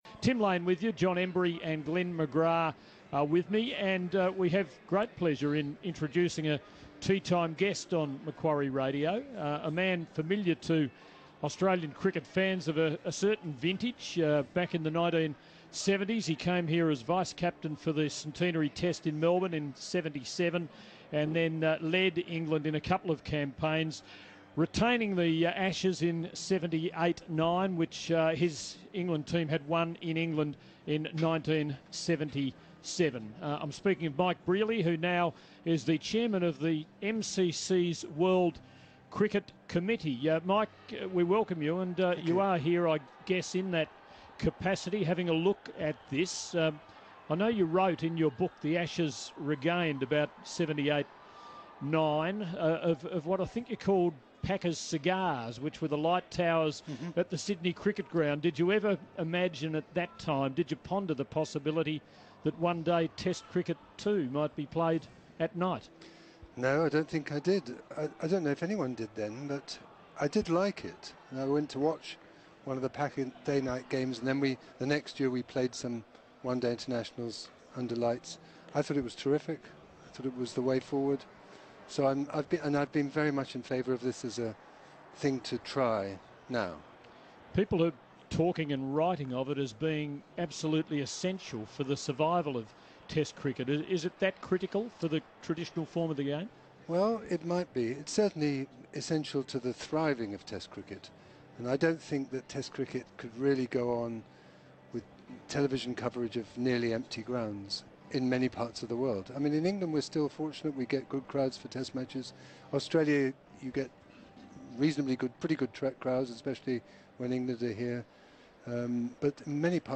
Mike Brearley talks to Tim Lane in the Macquarie Radio commentary box